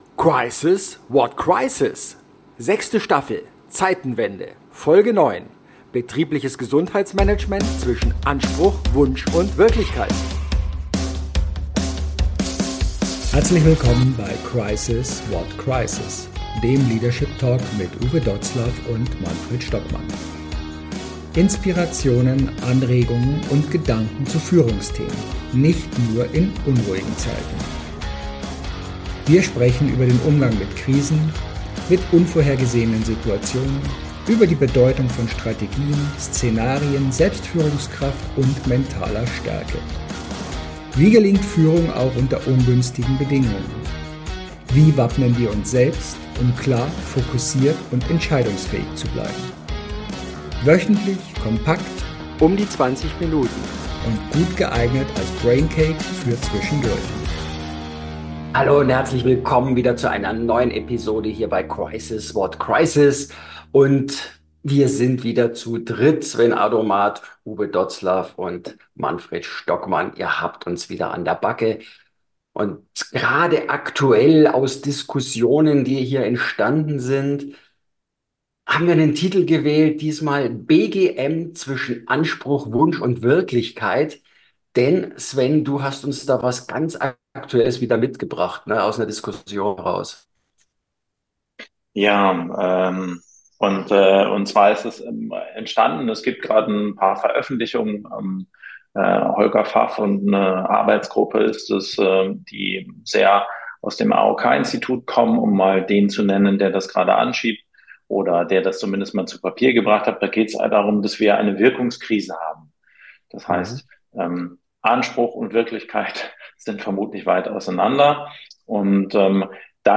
Auch in dieser Folge sind wir wieder zu dritt